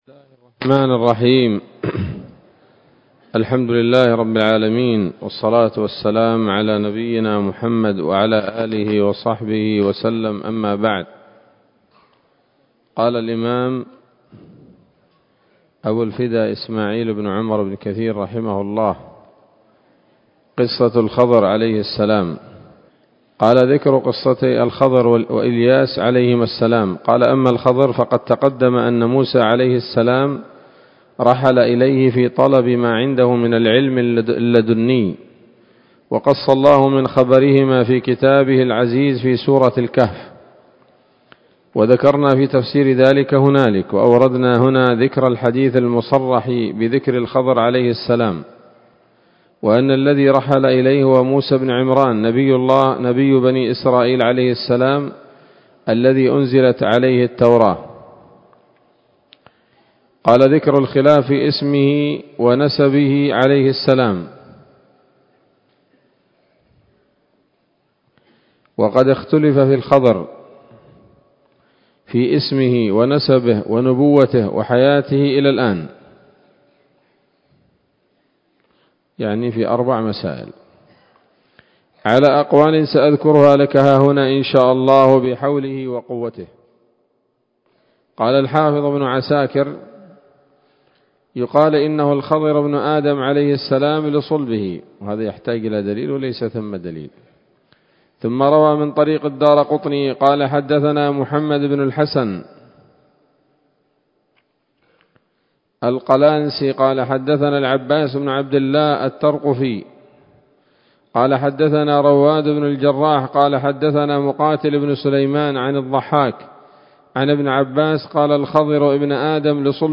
‌‌الدرس العاشر بعد المائة من قصص الأنبياء لابن كثير رحمه الله تعالى